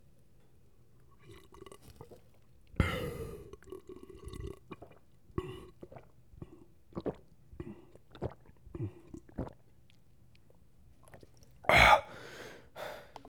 Thirsty Drinking Water Sound Effect Free Download
Thirsty Drinking Water